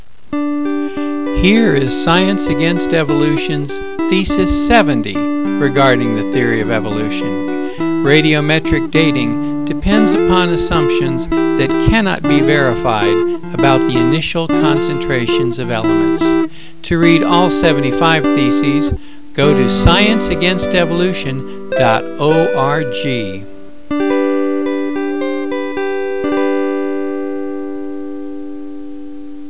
Radio Spots